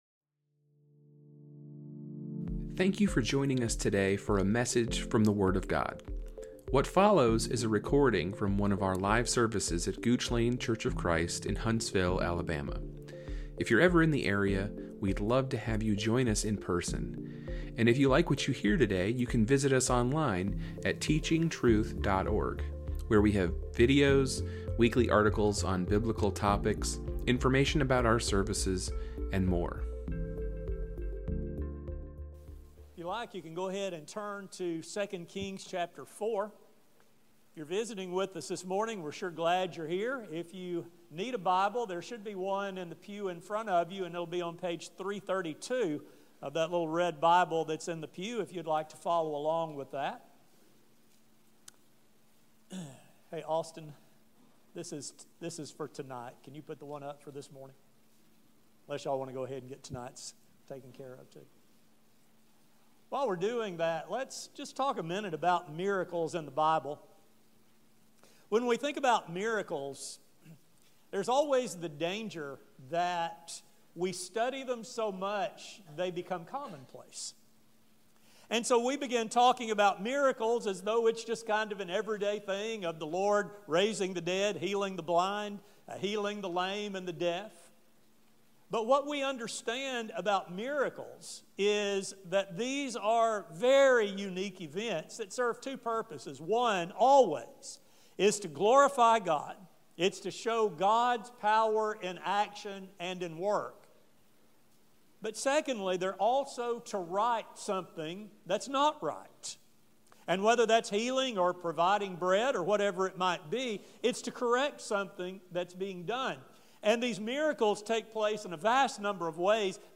This sermon will explore this account and amplify the important principles it speaks. A sermon